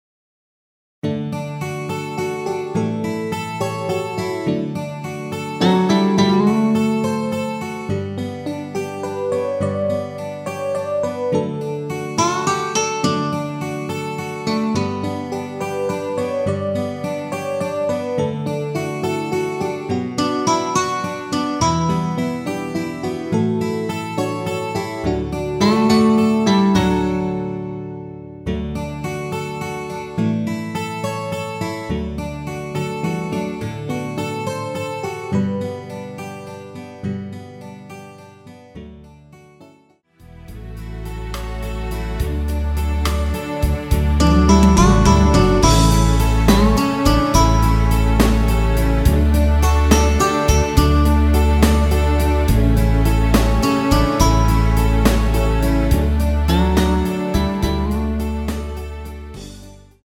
MR 입니다.
앨범 | O.S.T
앞부분30초, 뒷부분30초씩 편집해서 올려 드리고 있습니다.
중간에 음이 끈어지고 다시 나오는 이유는